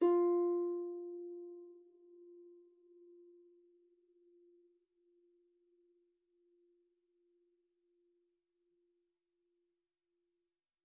KSHarp_F4_mf.wav